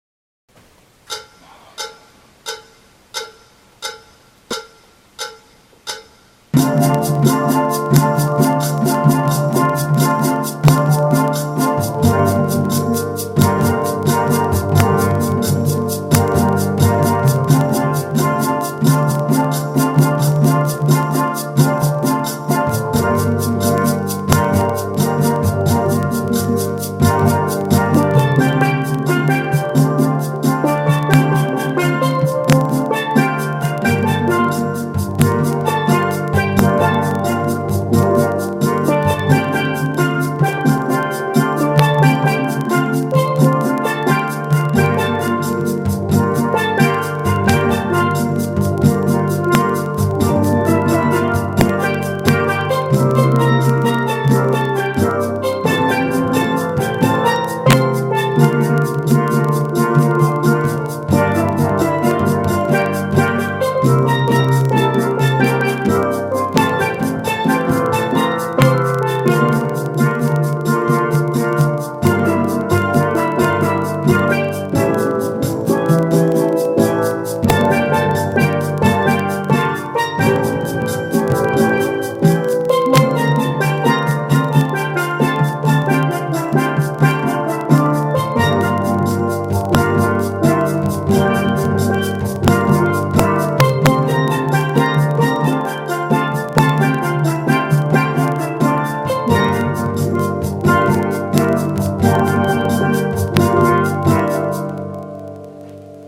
L’intro est filmée avant l’entrée des ténors plus un tour couplet/refrain à partir de l’entrée des ténors .
Rest la maloya VIDEO TUTTI 88 .mp3